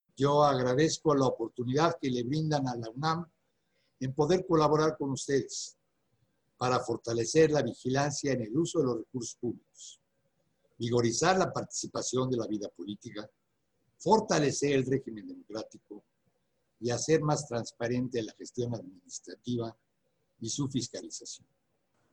“Todos los días deben ser días nacionales de lucha contra la corrupción”, afirmó el rector Enrique Graue Wiechers, durante la cátedra inaugural de la Especialidad en Fiscalización Gubernamental, que ofrece la UNAM a legisladores, funcionarios y servidores públicos de la Cámara de Diputados.